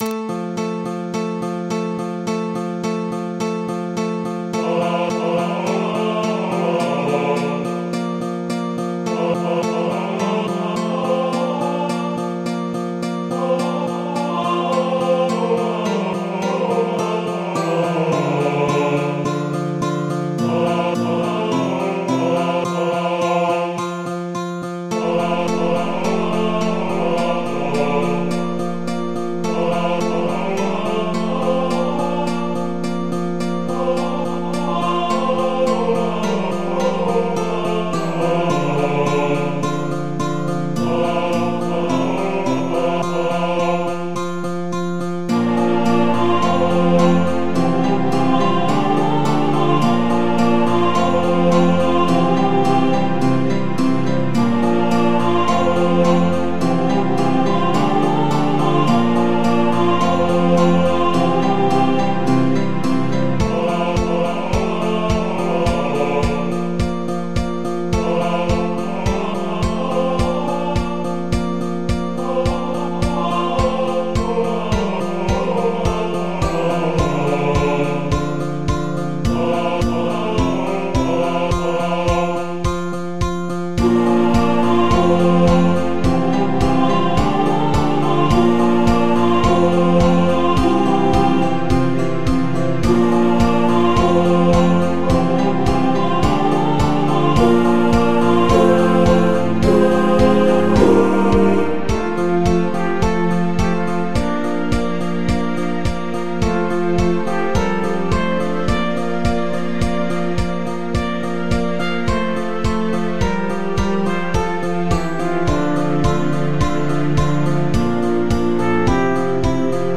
MIDI 33.06 KB MP3
very underhandedly nice ballad